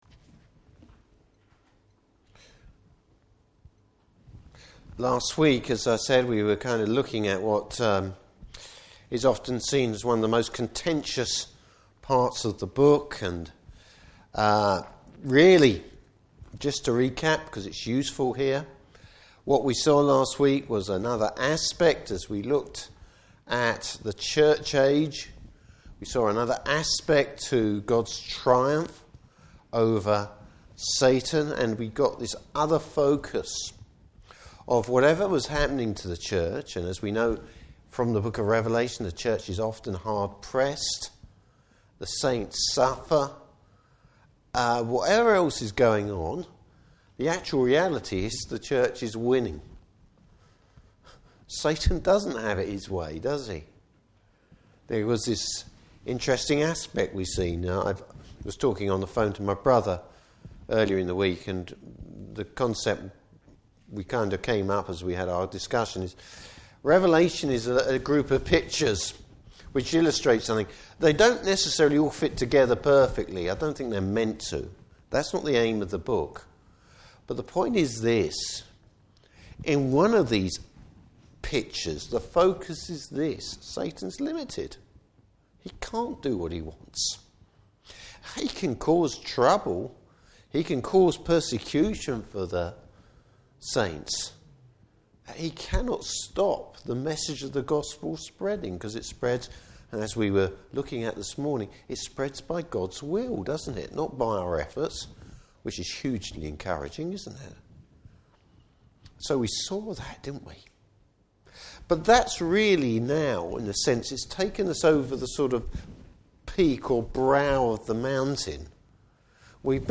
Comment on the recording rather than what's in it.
Service Type: Evening Service Bible Text: Revelation 21:1-21.